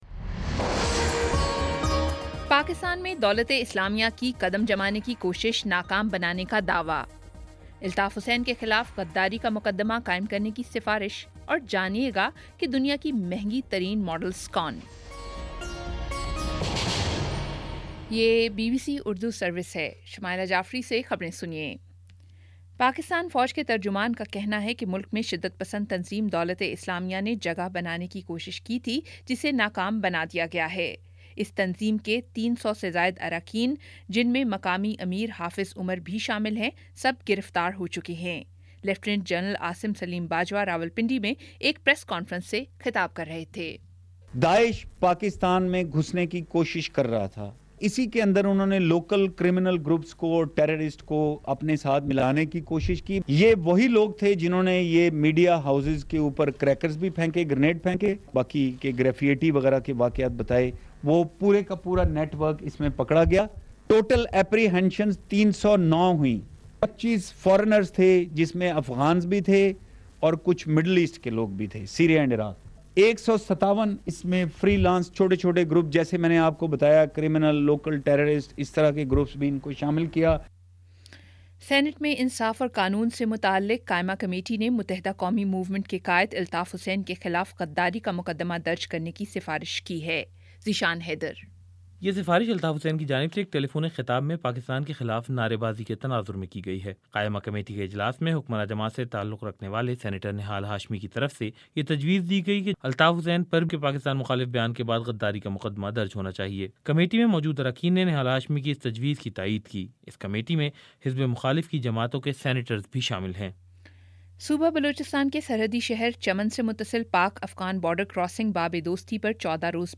ستمبر 01 : شام پانچ بجے کا نیوز بُلیٹن